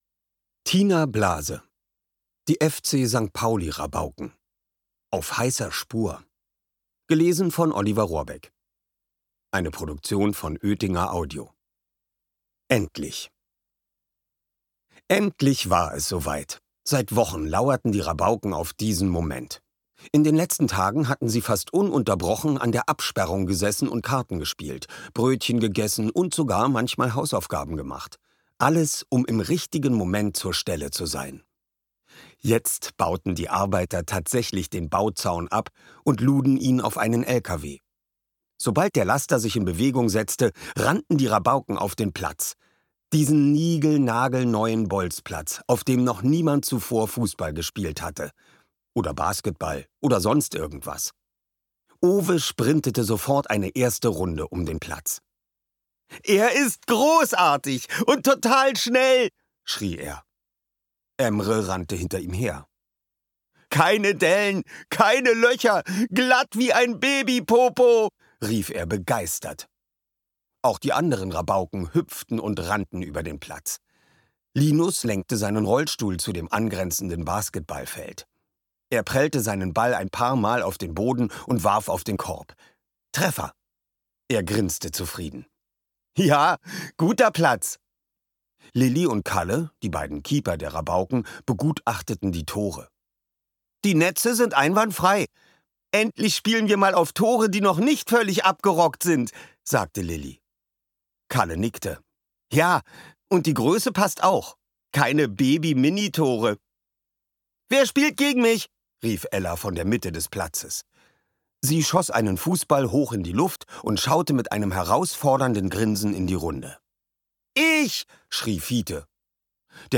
Hörbuch: FC St. Pauli Rabauken 3.